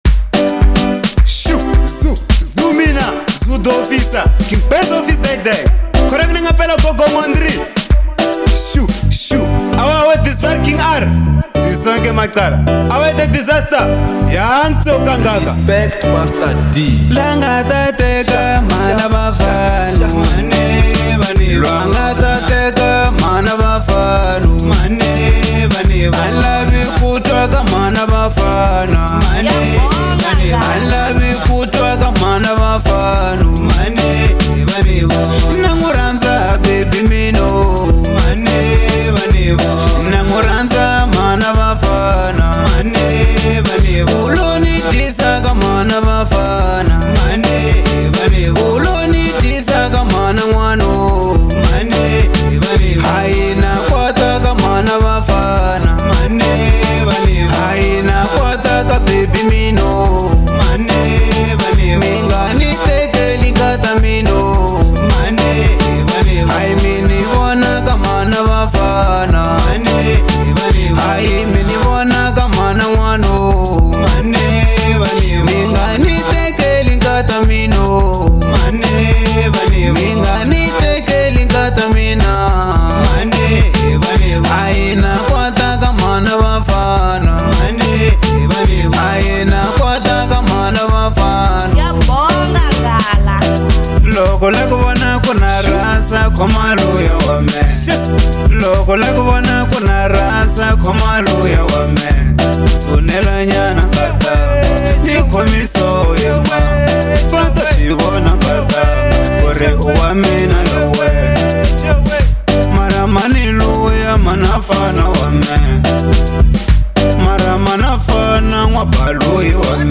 Genre : African Disco